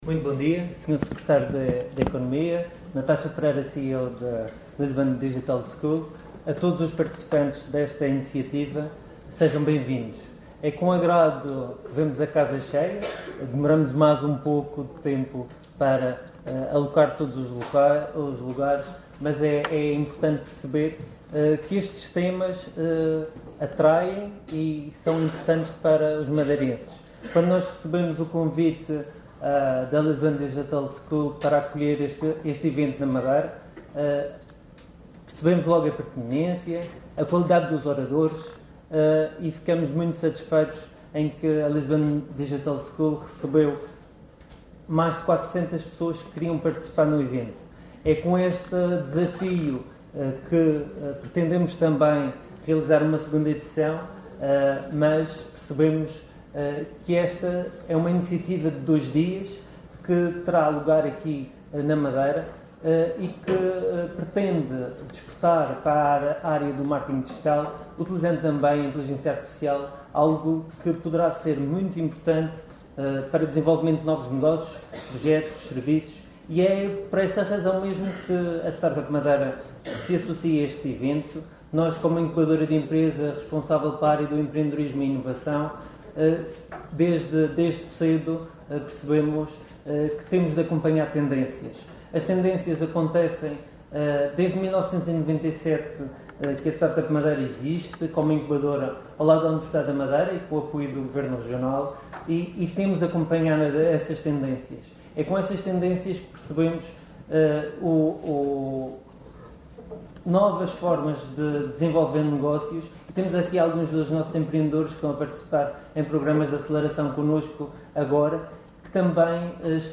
Sessão de abertura Everywhere Digital School (som)